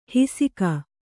♪ hisika